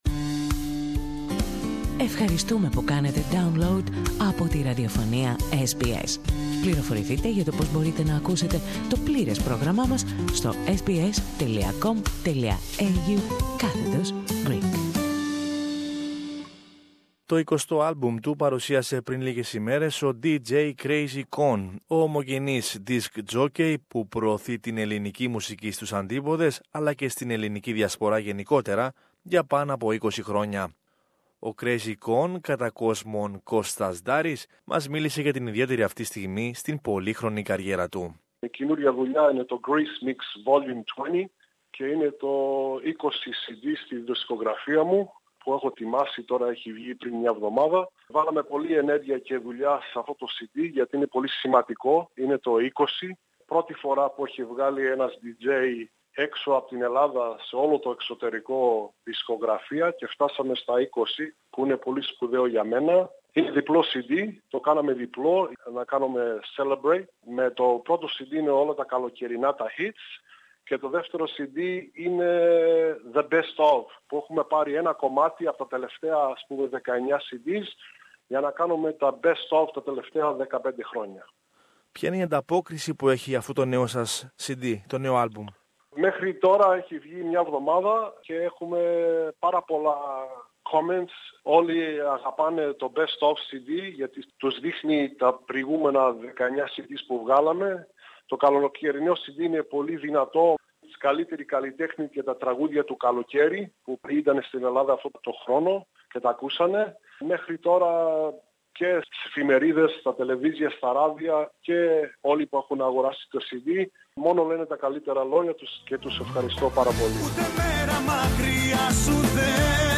More in this interview